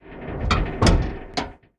ambienturban_5.ogg